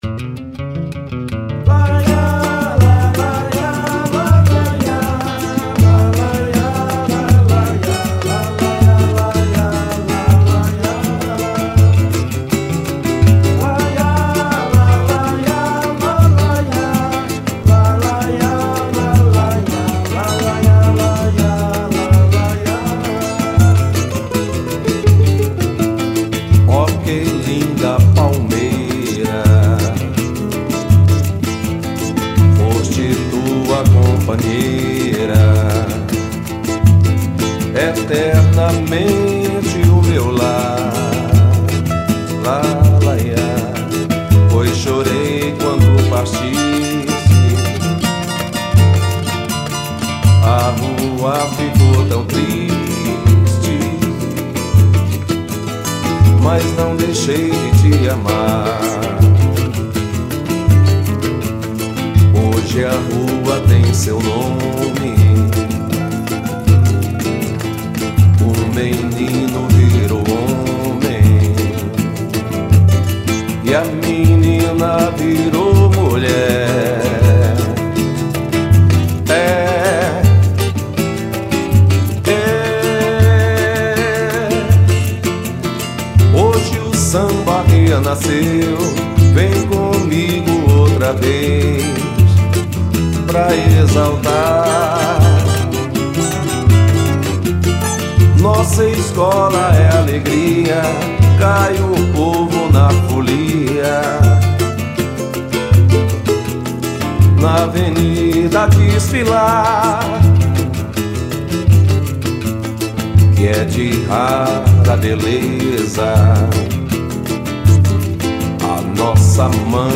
151   03:21:00   Faixa:     Samba